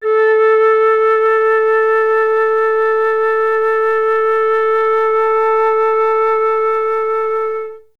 51c-flt04-A3.wav